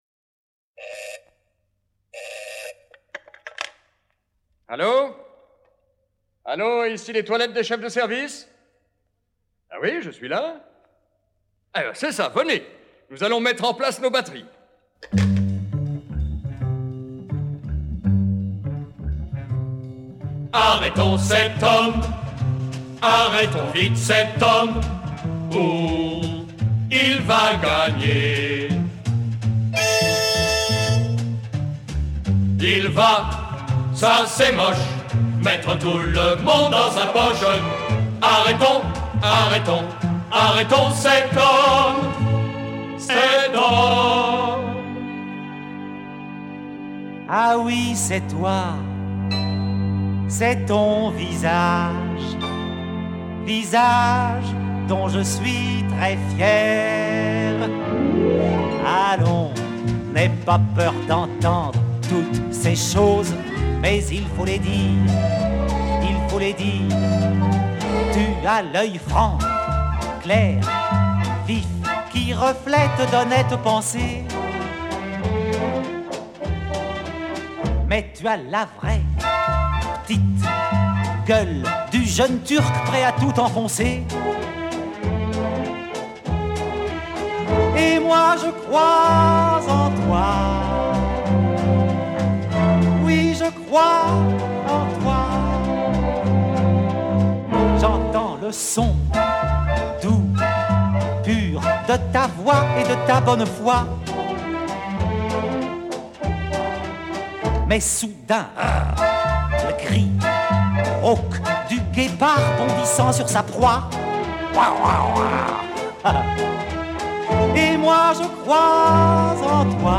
Original French Cast Recording